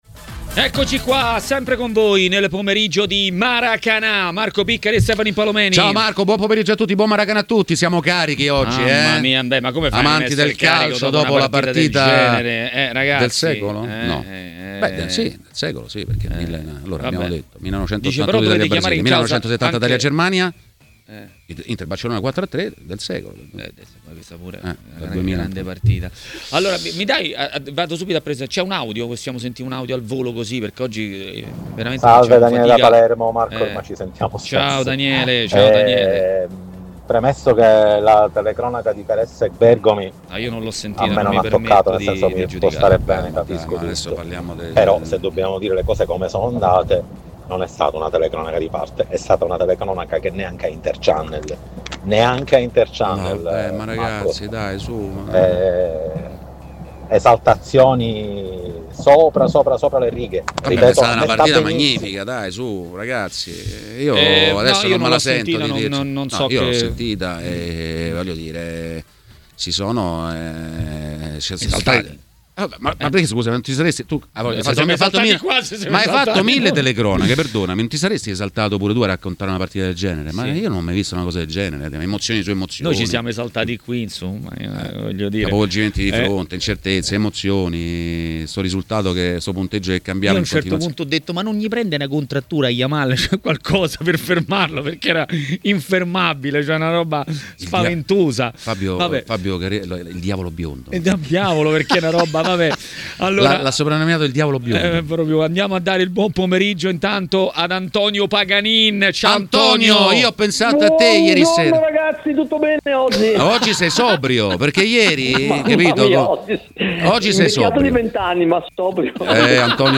Le Interviste
Ospite di Maracanà, trasmissione di TMW Radio, è stato l'ex calciatore Antonio Paganin.